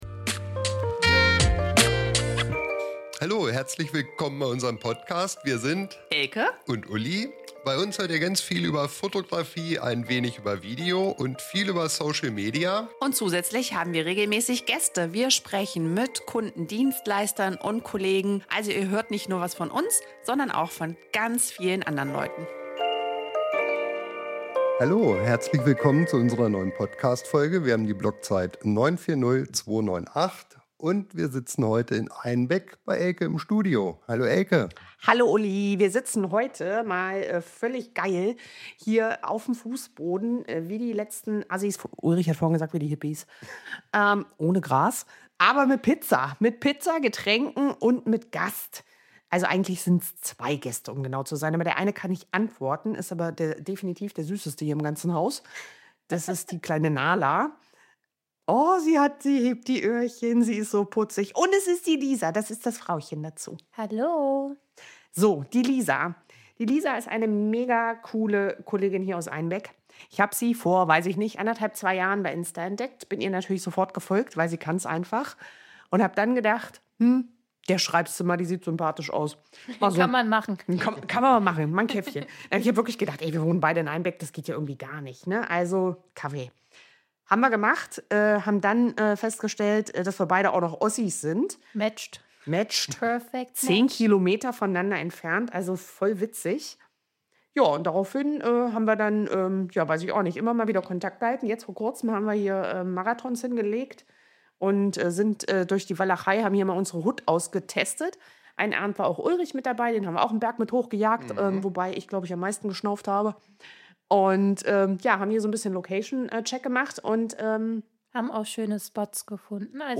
Gemeinsam haben wir ein spannendes und inspirierendes Gespräch geführt, das ihr auf keinen Fall verpassen solltet.